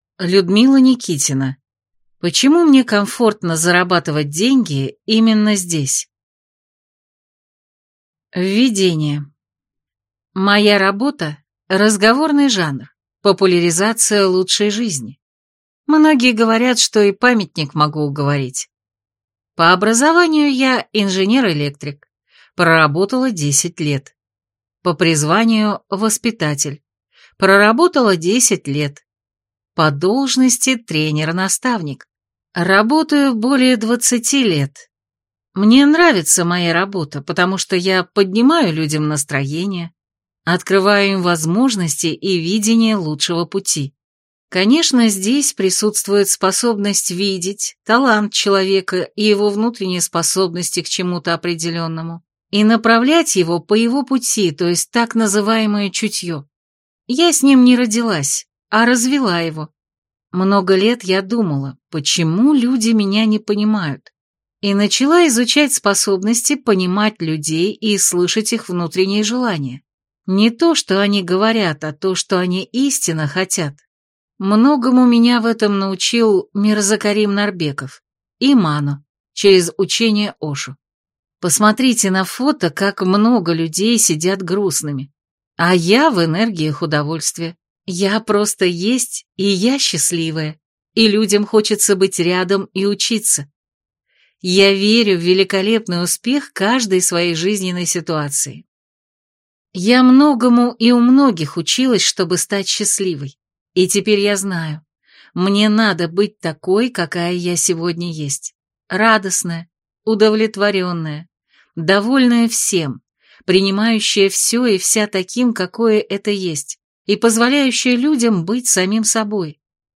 Аудиокнига Почему мне комфортно зарабатывать деньги именно здесь | Библиотека аудиокниг